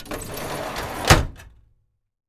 DRAWER1 CL-S.WAV